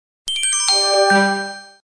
на смс
Метки: короткие,